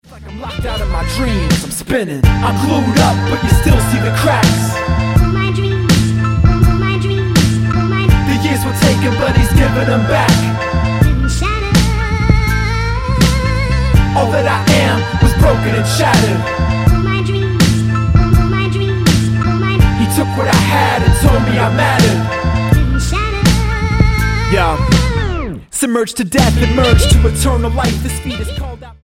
The US-born, UK-based rapper
Style: Hip-Hop